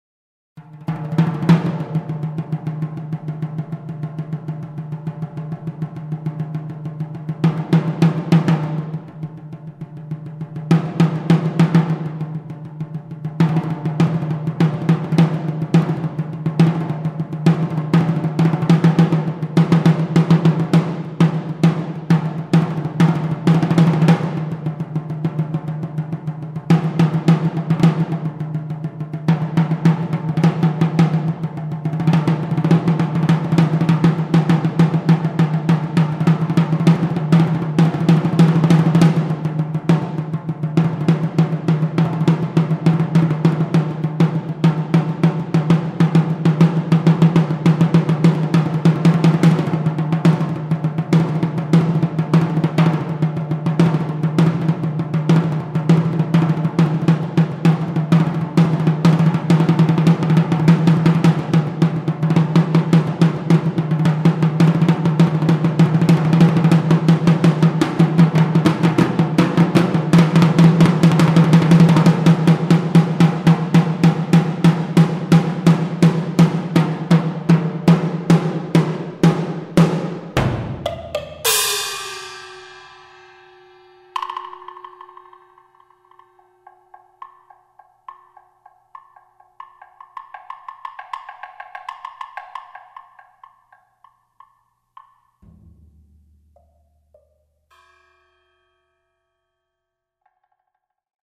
modern classical music for percussion